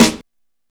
Snare (75).wav